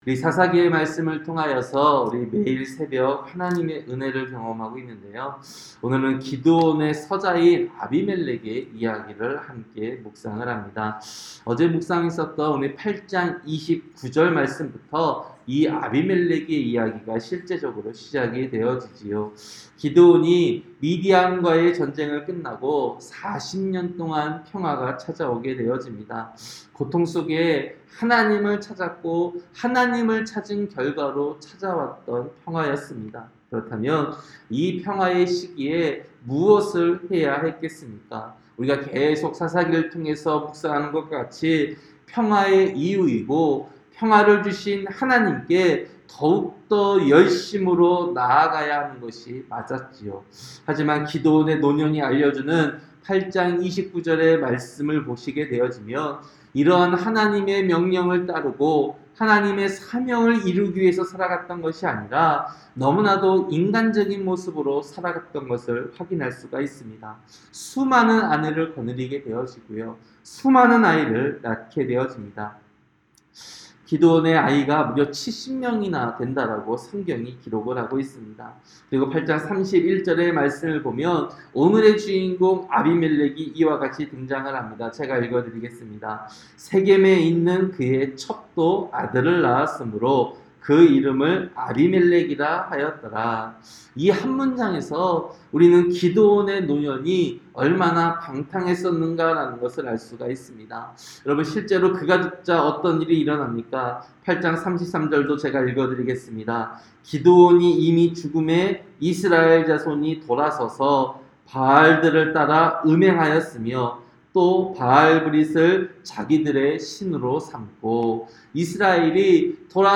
새벽설교-사사기 9장